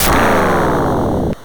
Sonic - Laser Fire Sound Buttons
laser_hBUSmJ9.mp3